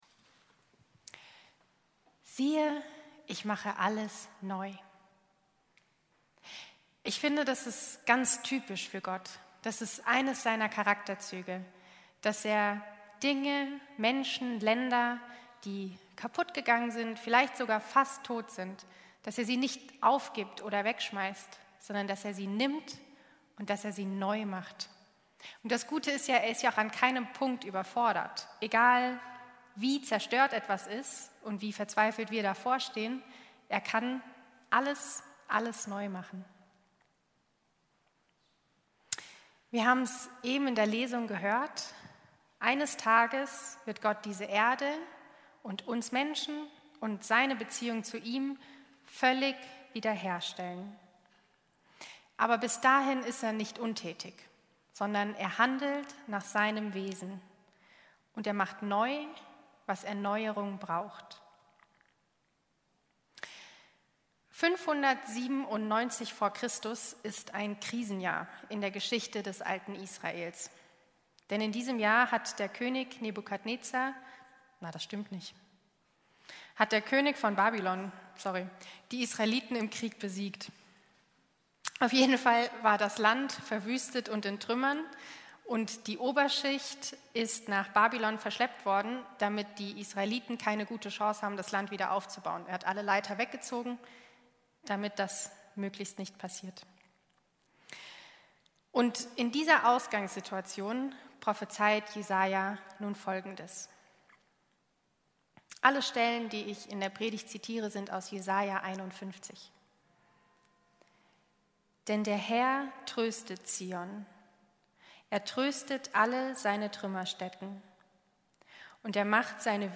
Wir werden in diesem Gottesdienst Gott den Tröster entdecken und um seinen Trost bitten.